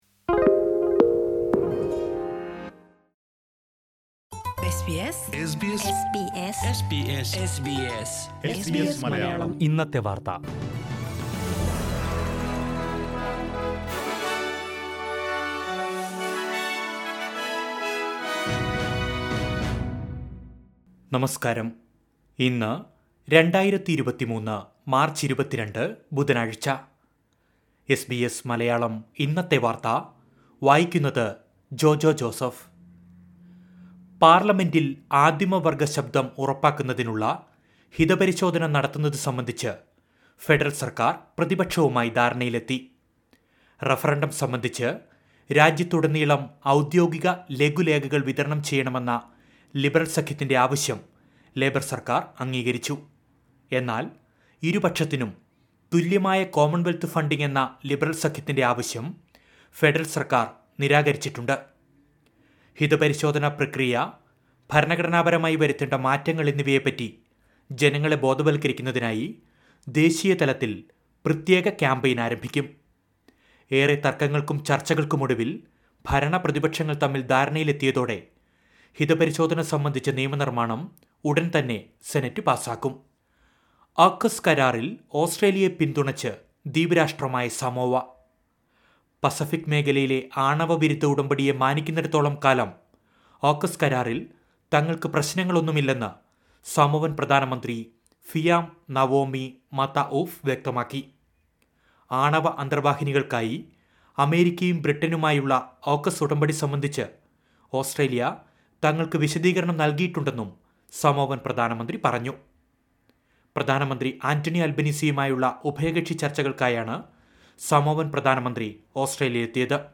2023 മാർച്ച് 22ലെ ഓസ്ട്രേലിയയിലെ ഏറ്റവും പ്രധാന വാർത്തകൾ കേൾക്കാം...